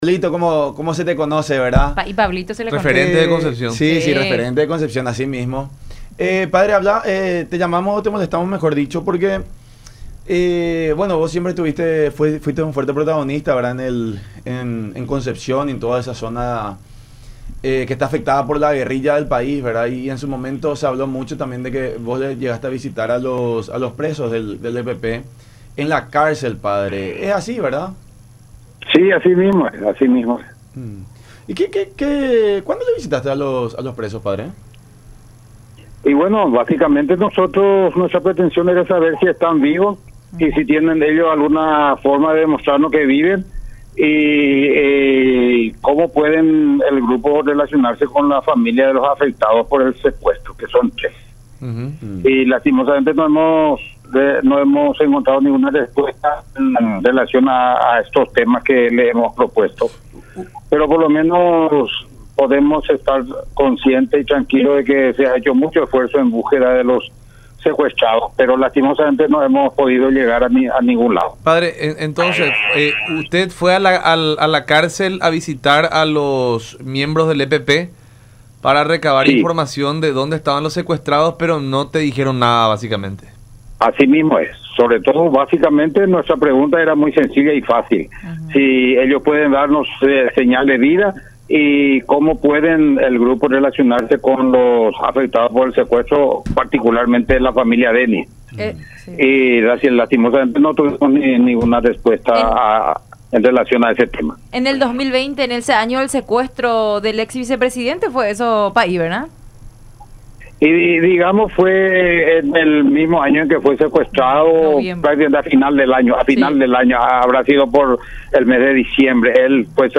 en conversación con La Unión Hace La Fuerza a través de Unión TV y radio La Unión